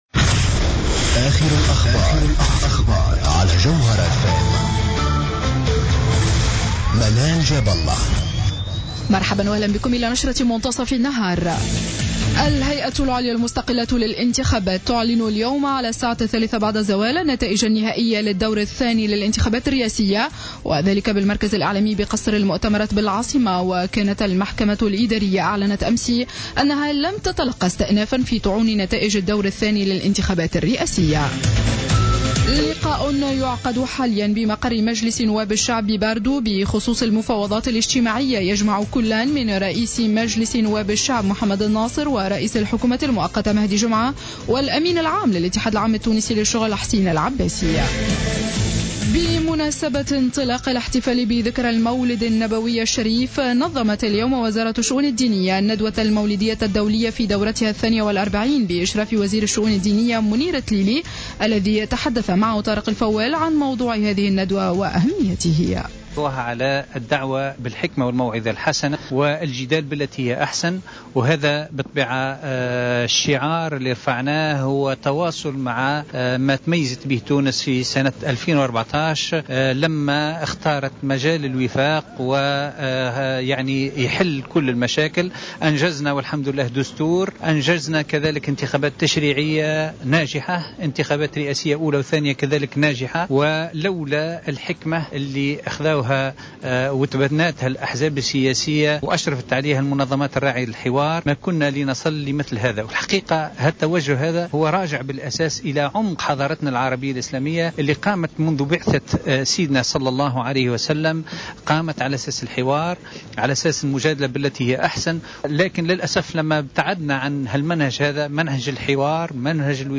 ،شرة أخبار منتصف النهار ليوم الاثنين 29-12-14